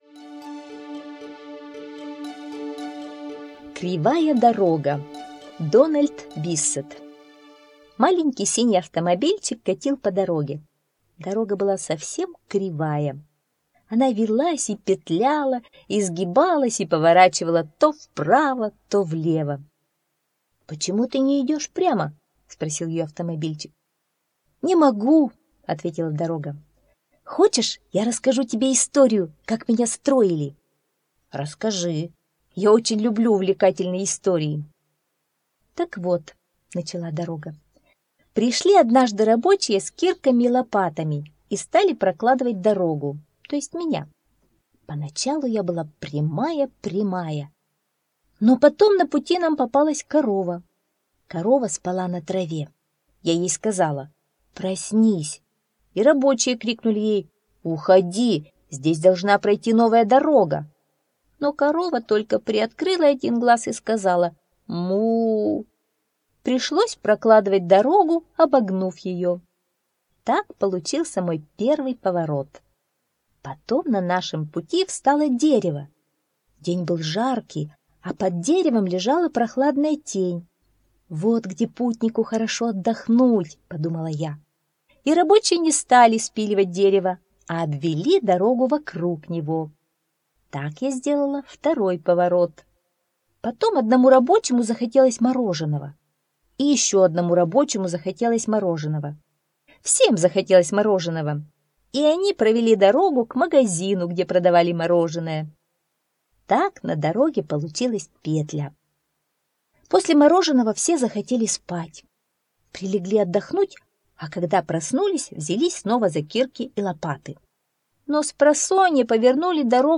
Кривая дорога - аудиосказка Биссета - слушать онлайн